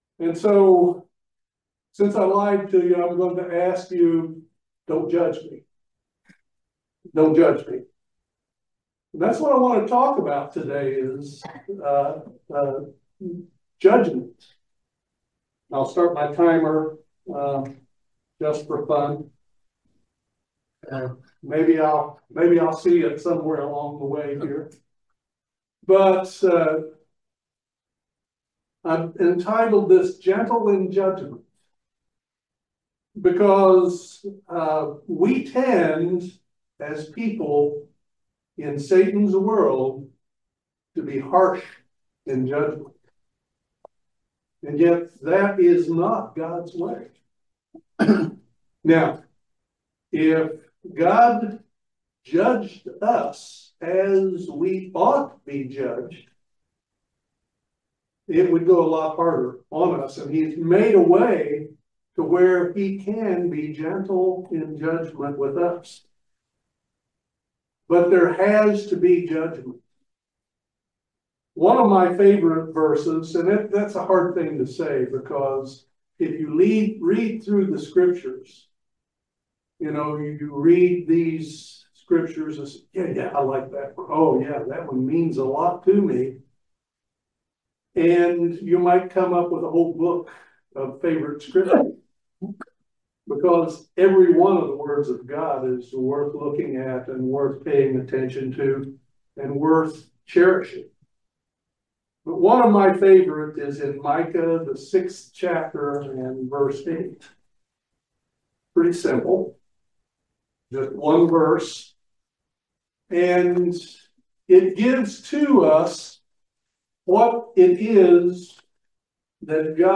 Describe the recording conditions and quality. Given in Lexington, KY